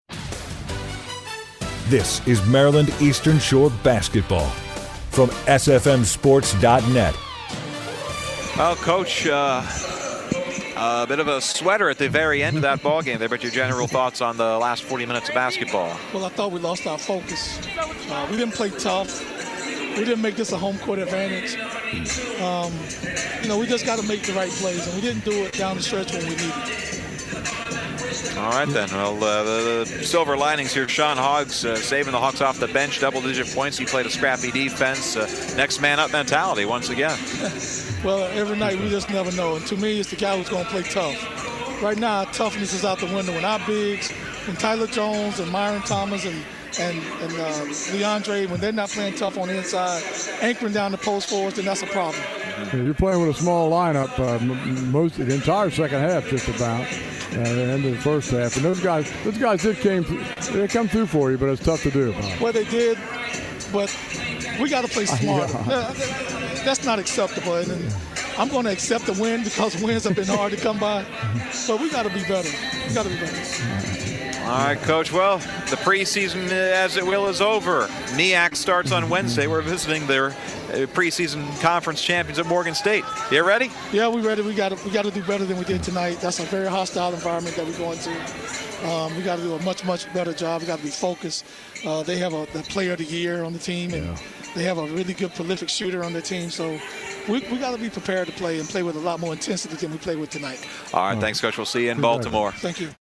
Post-Game Interview